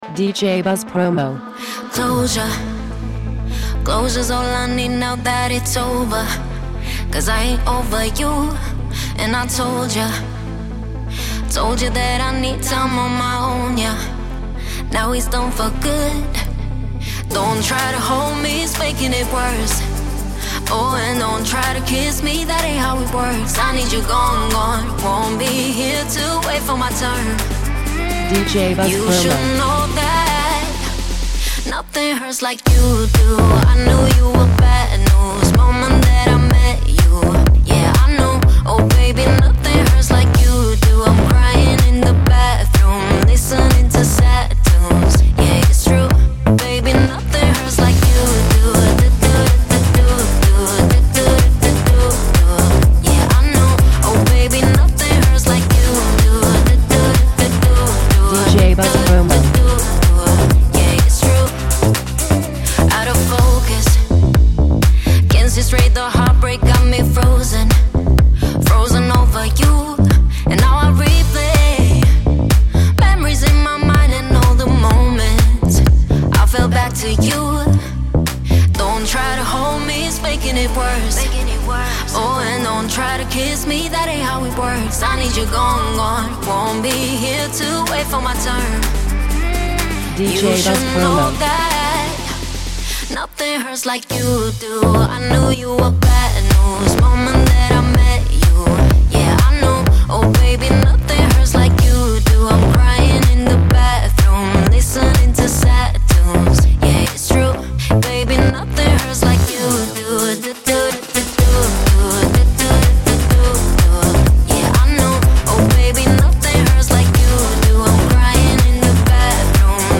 Massive Remix